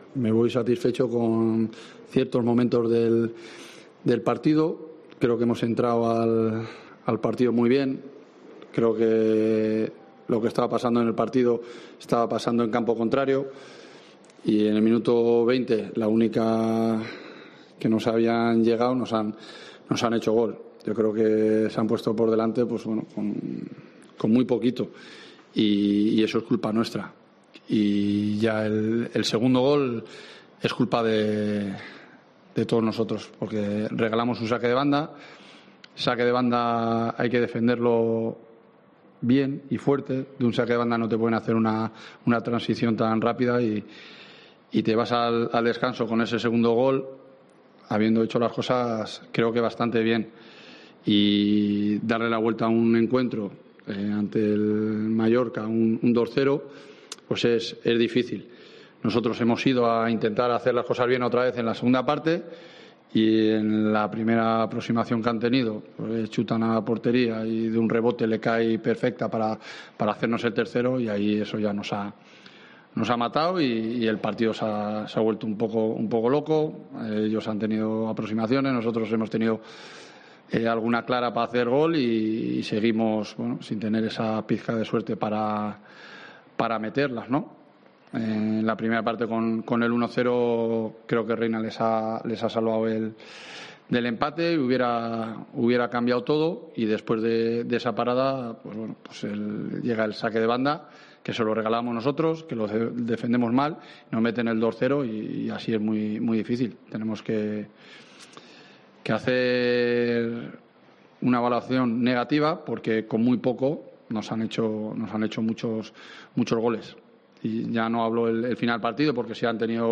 POSTPARTIDO
Escucha y lee aquí las palabras del entrenador de la Deportiva Ponferradina tras la derrota 3-0 en tierras baleares, en el estadio de Son Moix, ante el Mallorca